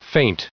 1668_feint.ogg